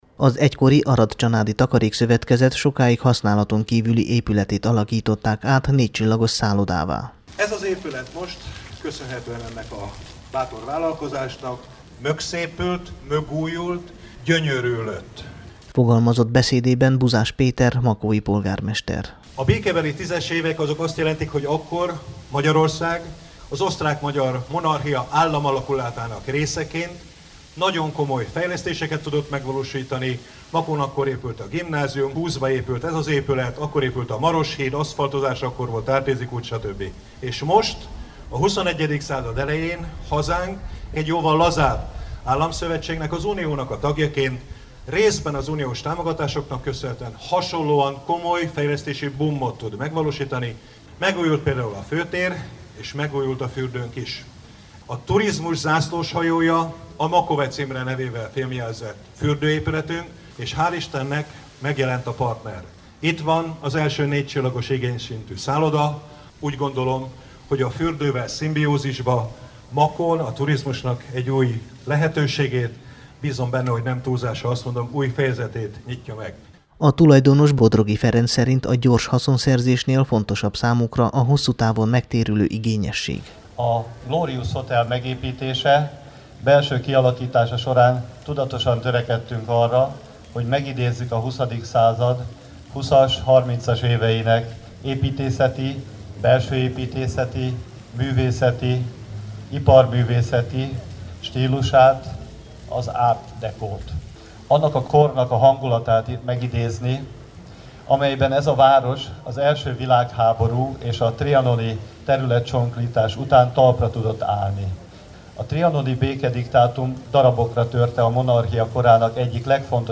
riportját, amely a Temesvári Rádió számára készült!